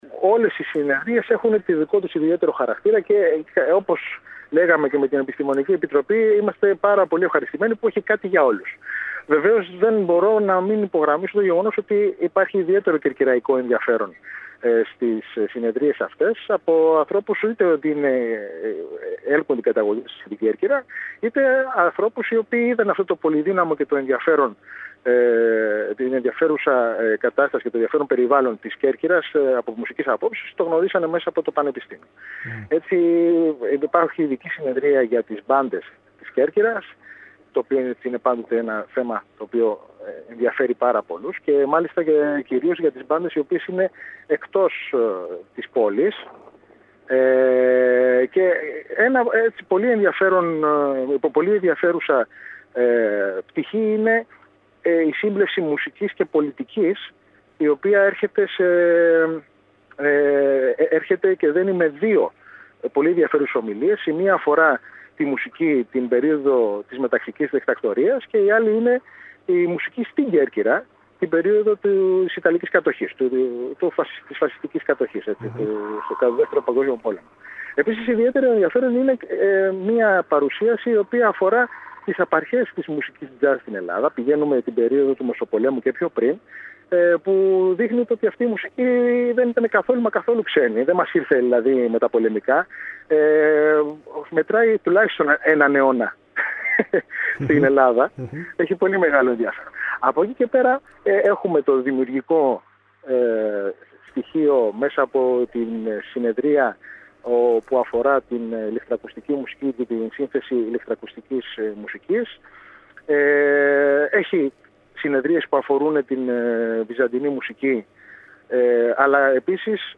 Περιφερειακοί σταθμοί ΚΕΡΚΥΡΑ